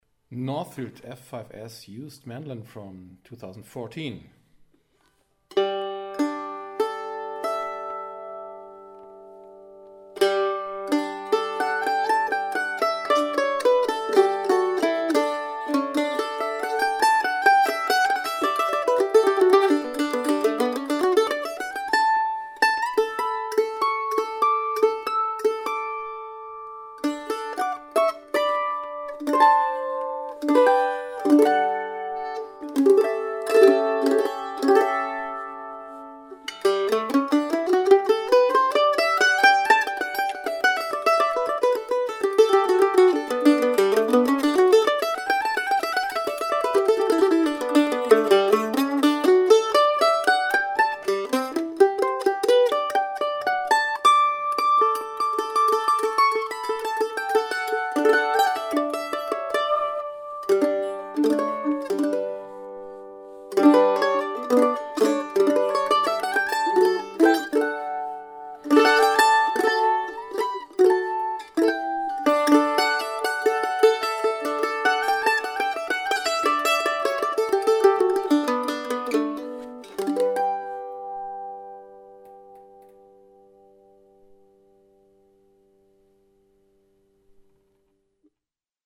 SOUND CLIPS - MANDOLIN